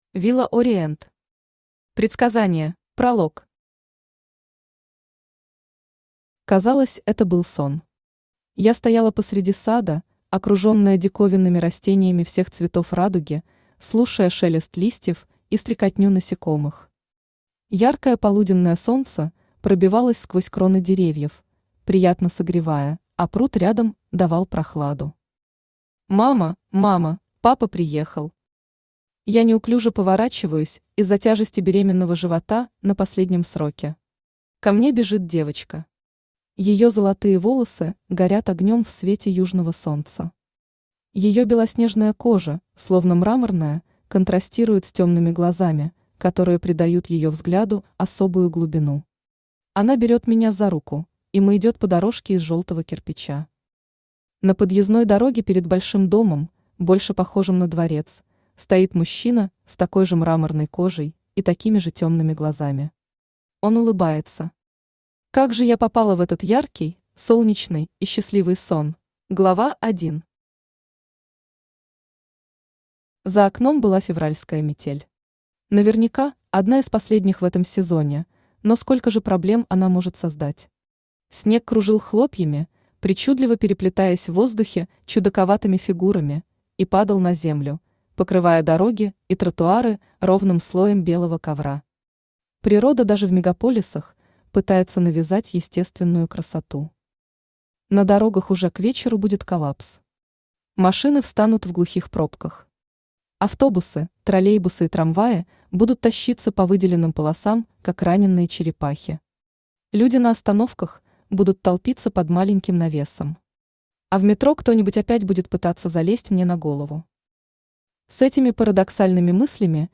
Aудиокнига Предсказание Автор Villa Orient Читает аудиокнигу Авточтец ЛитРес.